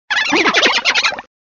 Fichier:Cri 0453 DP.ogg
contributions)Televersement cris 4G.